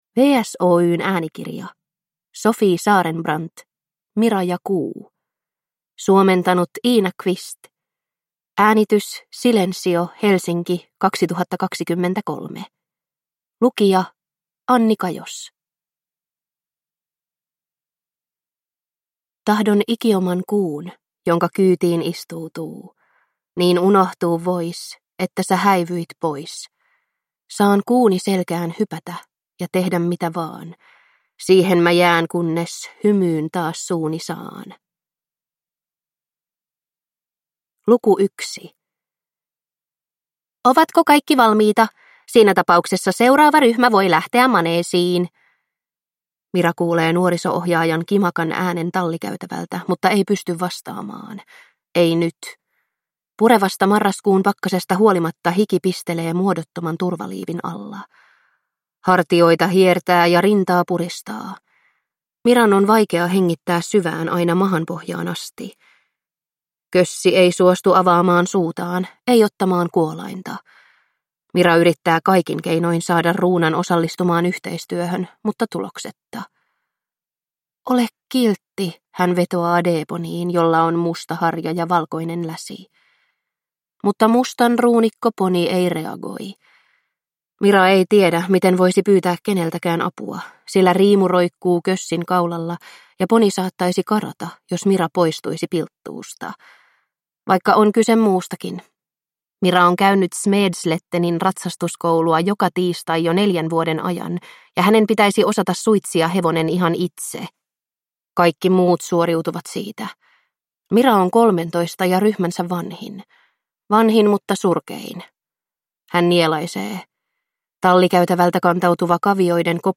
Mira ja Kuu – Ljudbok – Laddas ner